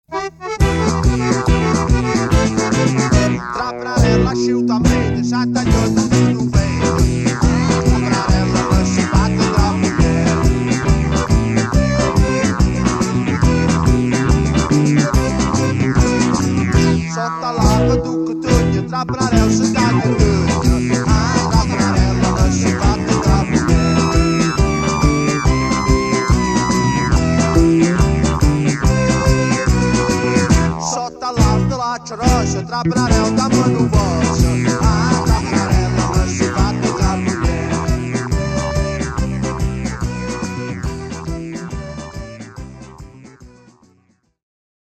oggi viene riproposto in versione rimasterizzata.